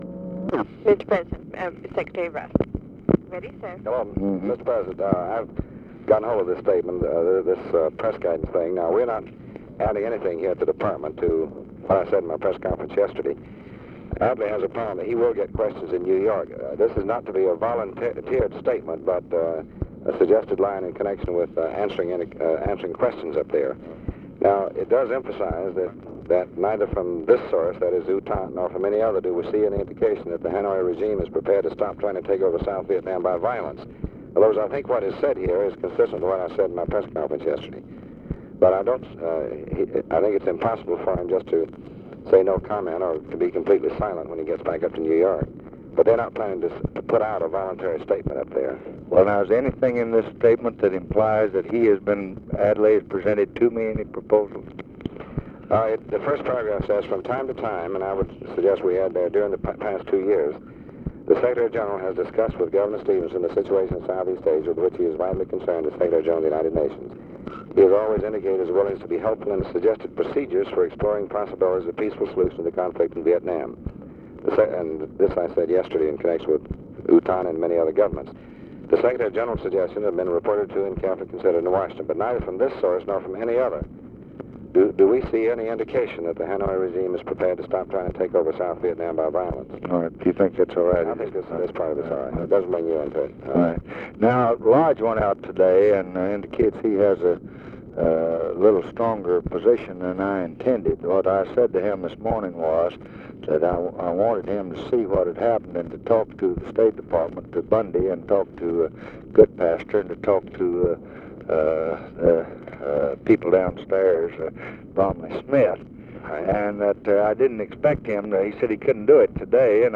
Conversation with DEAN RUSK, February 26, 1965
Secret White House Tapes